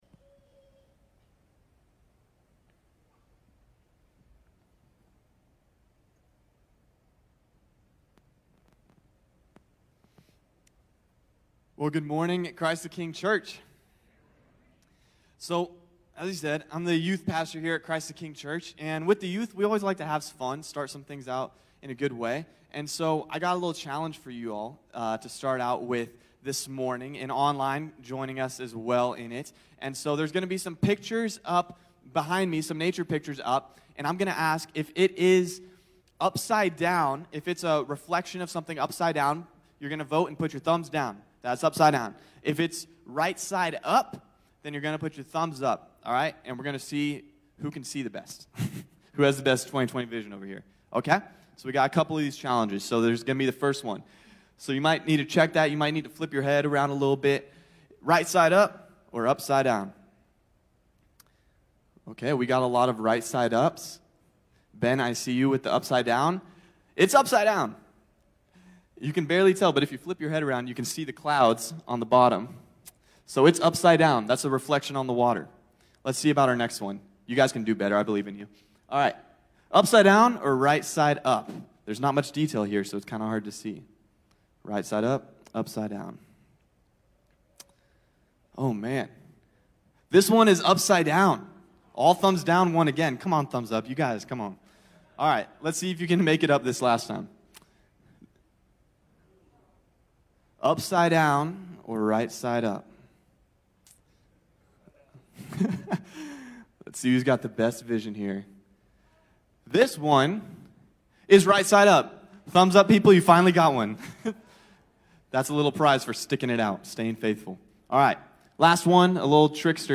CTK-Sermon-Aug-22-1.mp3